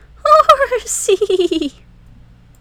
infinitefusion-e18/Audio/SE/Cries/HORSEA.wav at releases-April